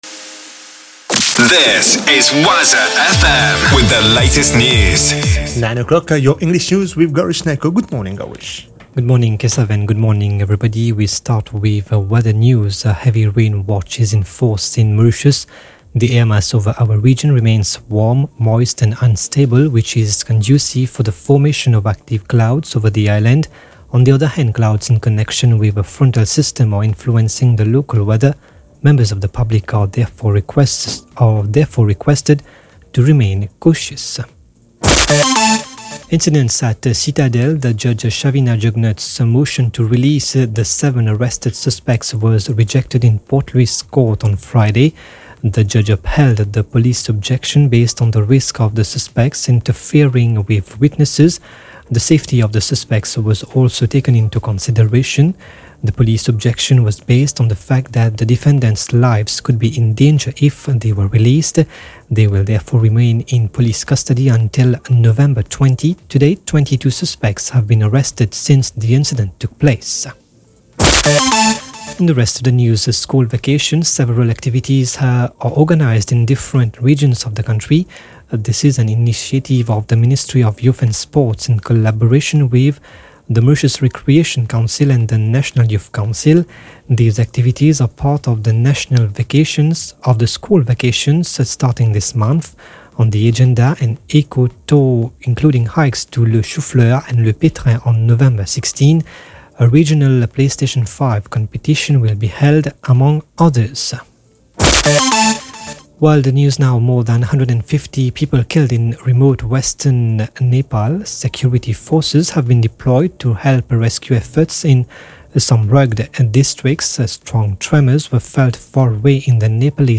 news 9h - 5.11.23